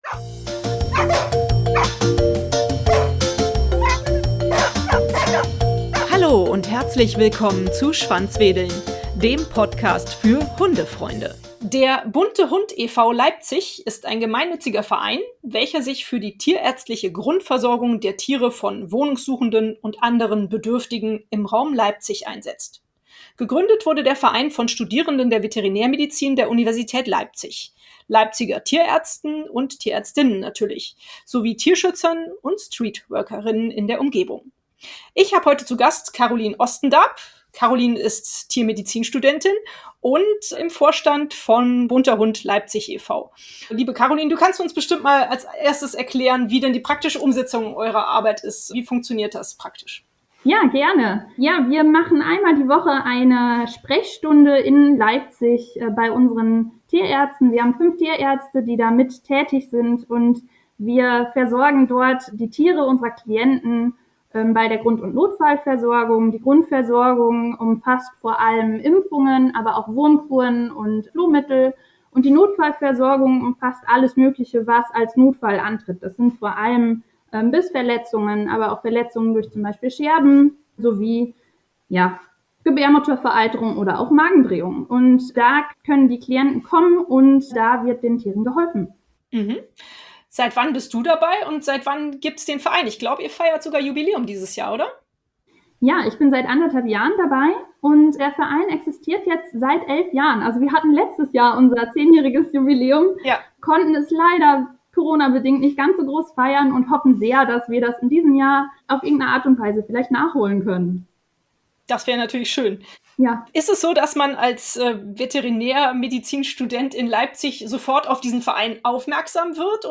Bei mir heute zu Gast die Tiermedizinstudentin